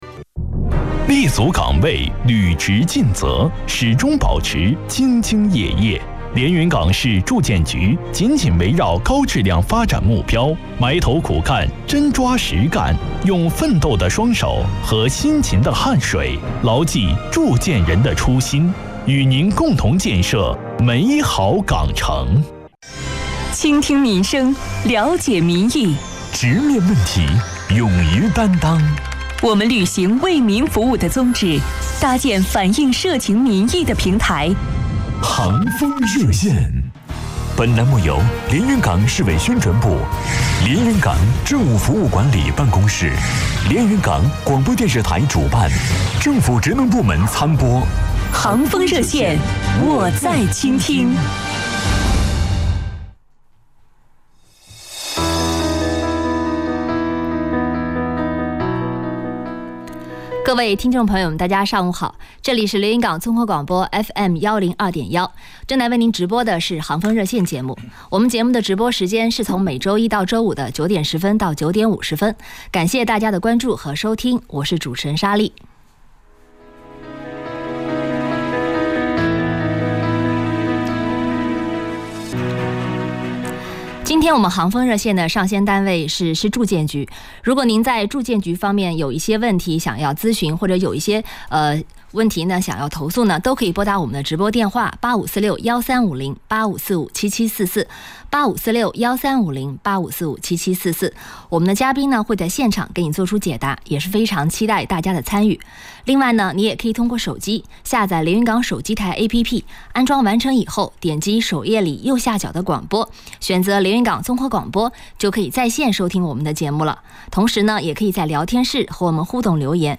2020年3月行风热线上线领导：市住建局党委委员、副局长叶磊明 特邀嘉宾 市住建局党委委员、副局长叶磊明